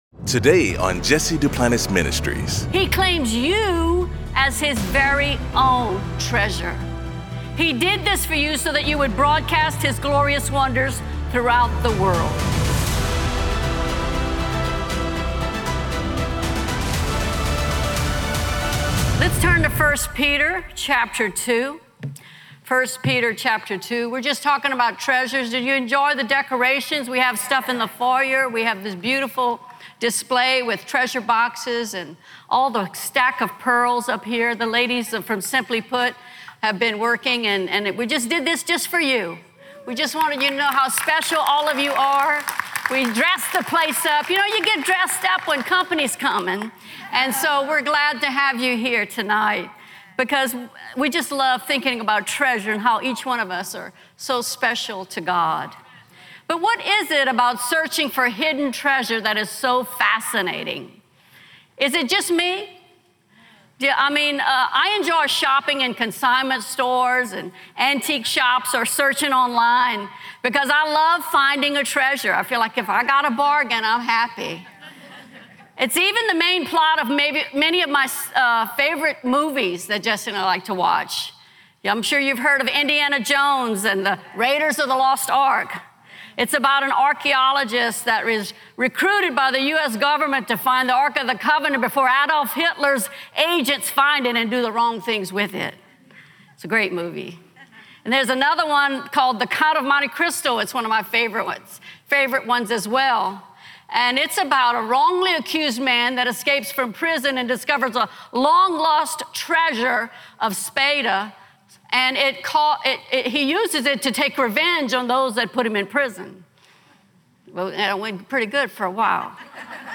Get stirred up with this transforming teaching